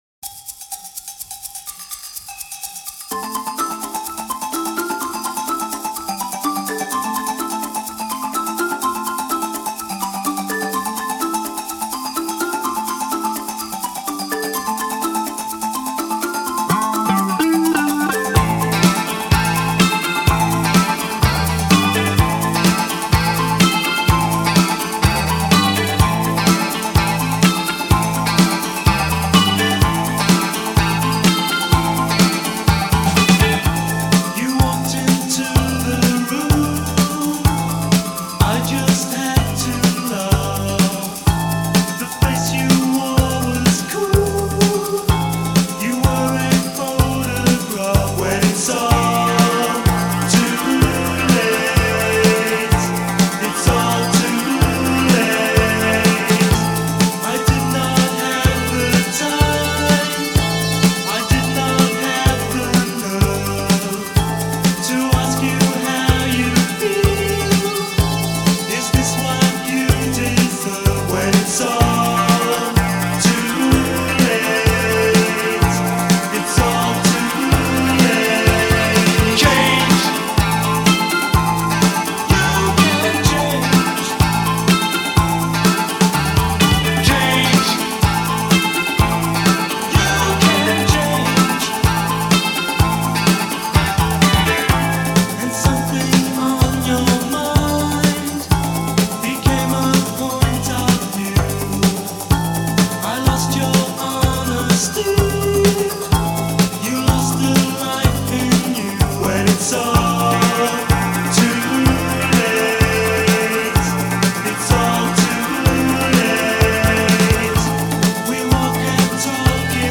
Here are five great new wave cuts from 1983.